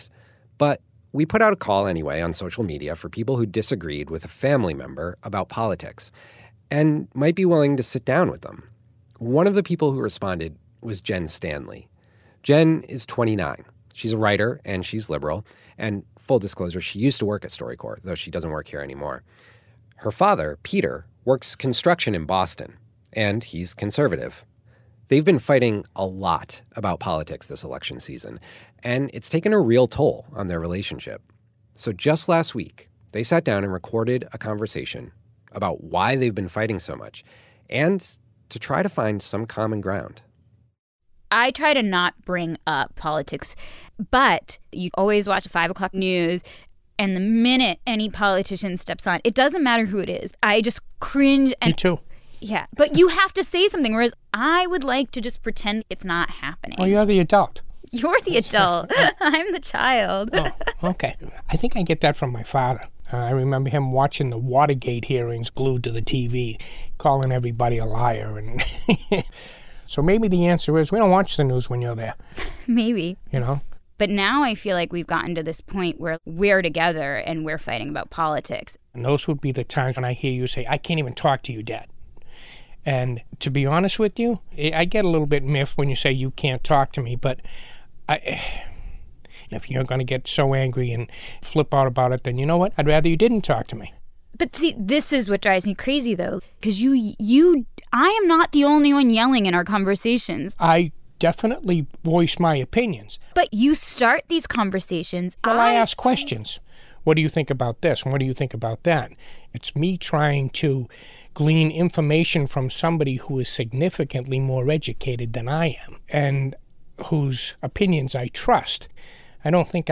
I’m hearing real conversations between people that reinforce my faith in the fundamental goodness of human beings. The following StoryCorps audio clip is particularly relevant to this post:
I’m glad that they were able to finally just talk.